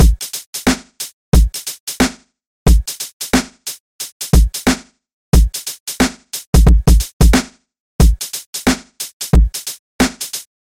陷阱大鼓
描述：踢腿小鼓嗨帽低音鼓。
Tag: 145 bpm Trap Loops Drum Loops 1.11 MB wav Key : Unknown FL Studio